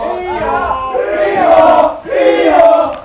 Here it is: You have also won the chance to hear the secret chant , only available to skilful people like yourself.